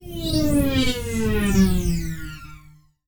Пролет космического корабля Скачать звук music_note Sci-fi , Фантастика save_as 105.4 Кб schedule 0:03:00 5 0 Теги: mp3 , Sci-Fi , звук , звуковые эффекты , космический транспорт , космос , пролет , Фантастика